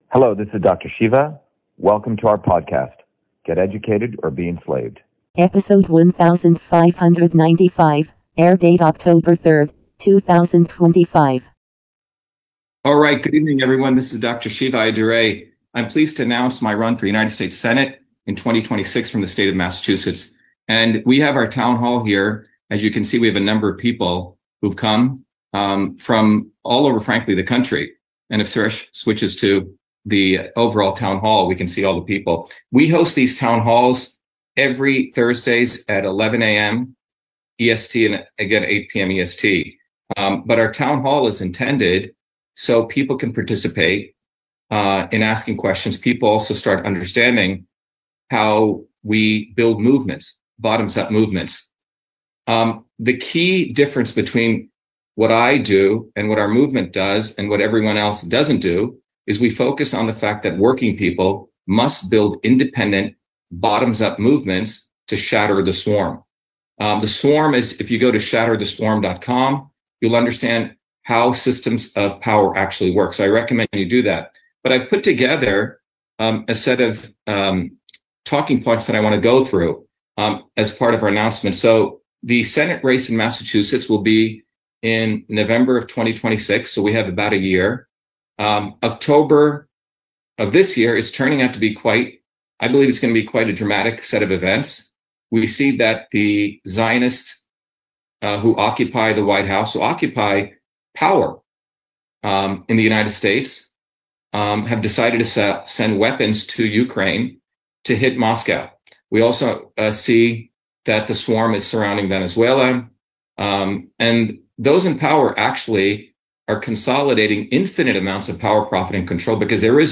In this interview, Dr.SHIVA Ayyadurai, MIT PhD, Inventor of Email, Scientist, Engineer and Candidate for President, Talks about U.S. SENATE 2026 Announcement.